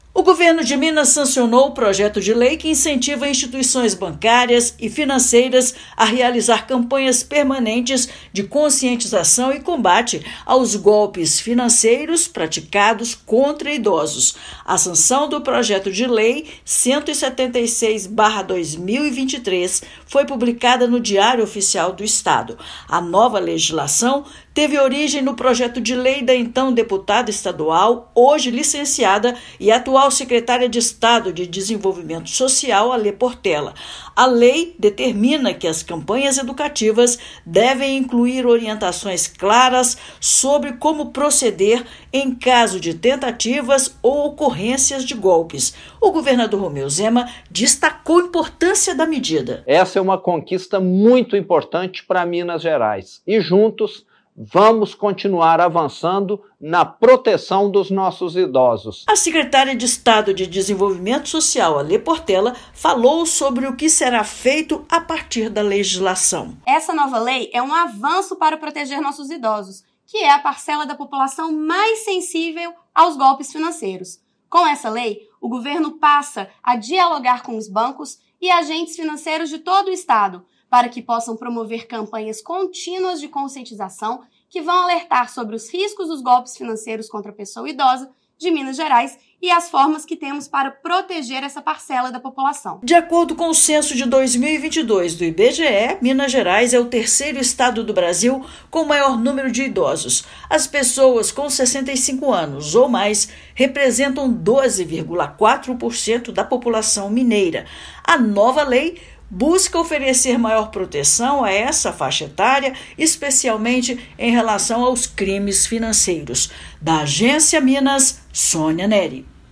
[RÁDIO] Governo de Minas sanciona lei para combate a golpes financeiros contra idosos
Nova legislação incentiva bancos e instituições de crédito a promover campanhas educativas para orientar essa parcela da população sobre os tipos mais comuns de fraudes. Ouça matéria de rádio.